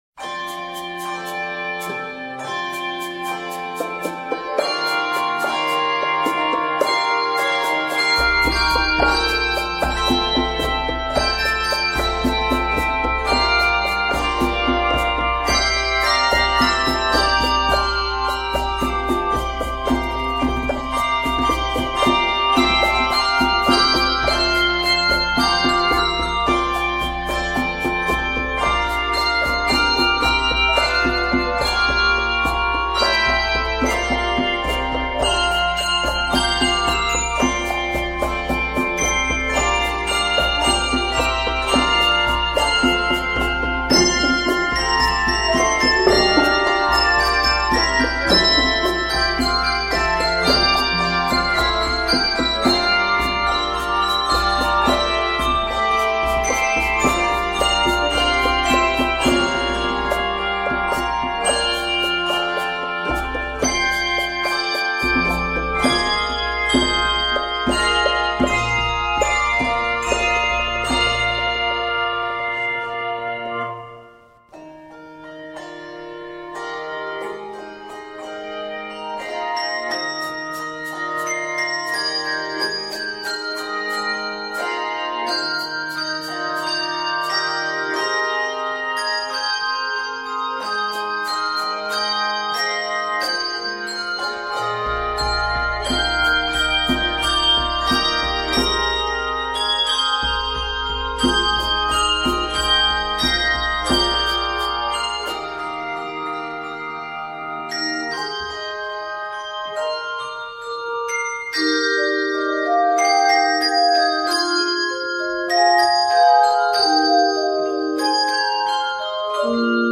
From the rhythmic opening to the dynamic ending